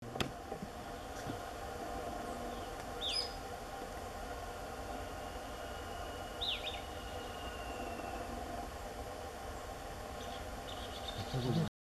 Greenish Elaenia (Myiopagis viridicata)
Life Stage: Adult
Location or protected area: Reserva Privada y Ecolodge Surucuá
Condition: Wild
Certainty: Recorded vocal